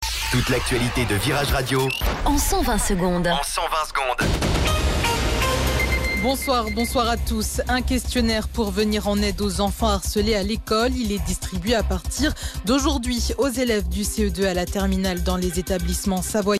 Flash Info Chambéry